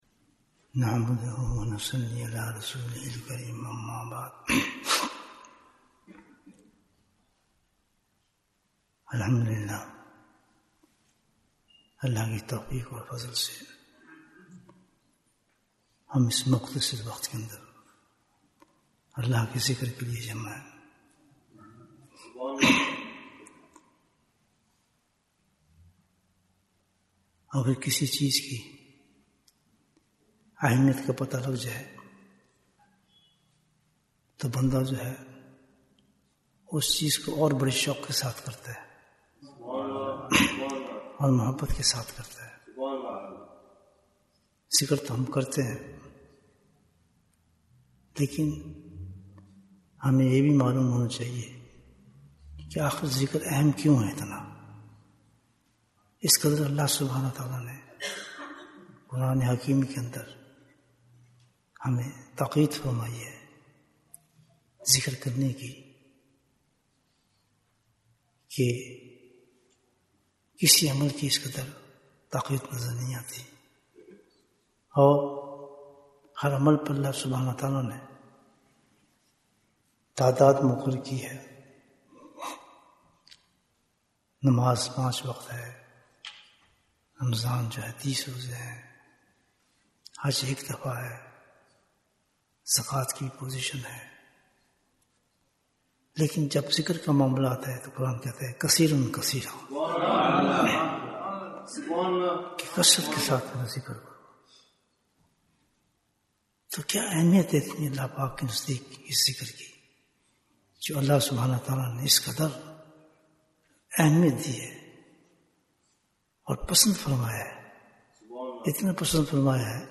Bayan, 40 minutes23rd May, 2024